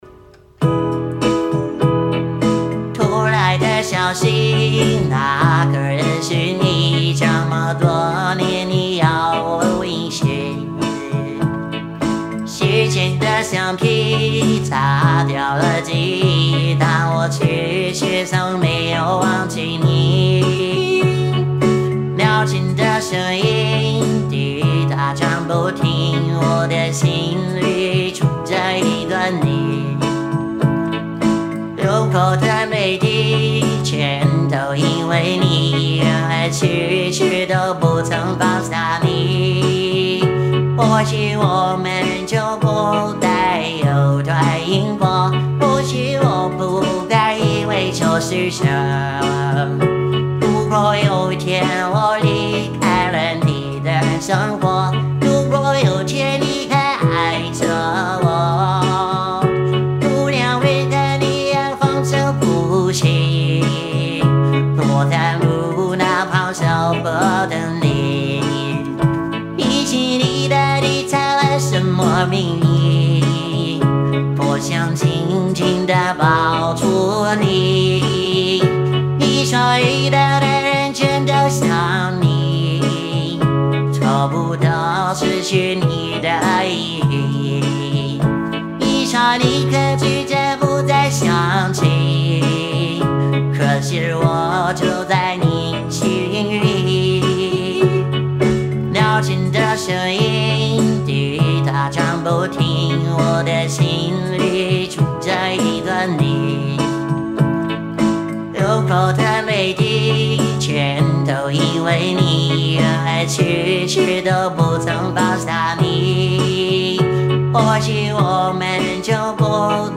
翻唱版